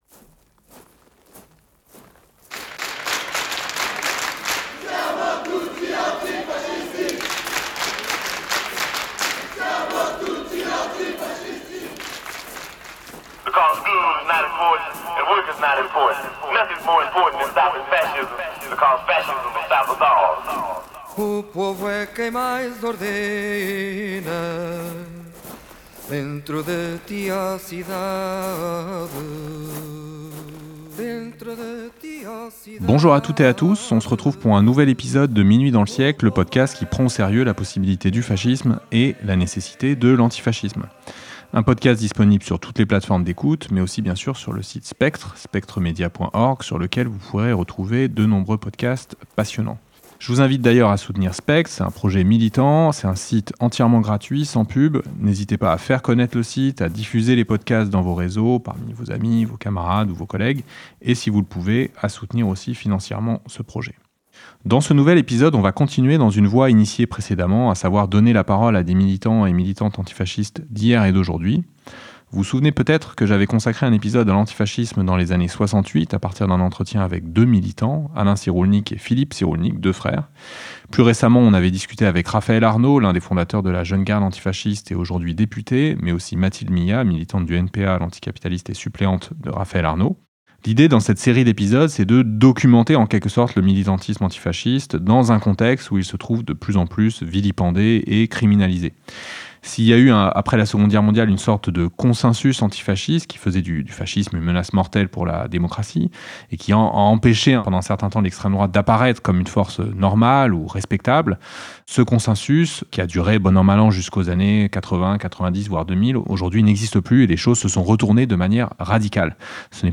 Dans cet épisode, je discute avec des militants du Comité antifa de Saint-Étienne, u